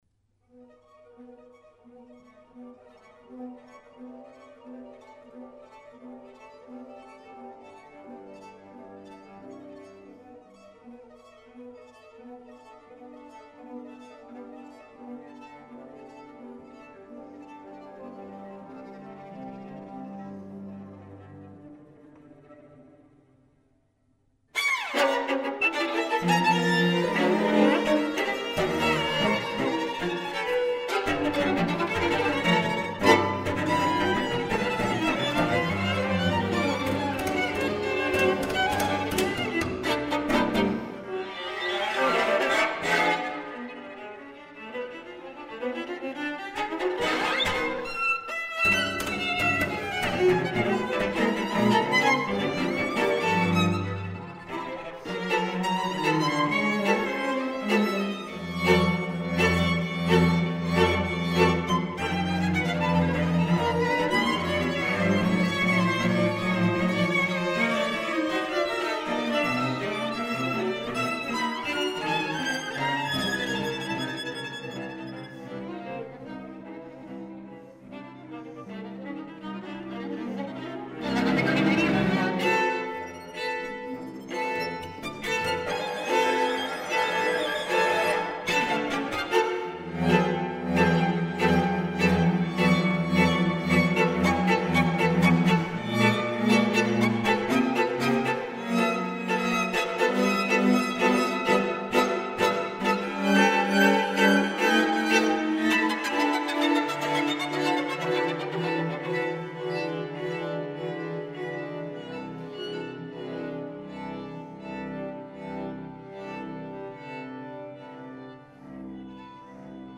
Contemporary.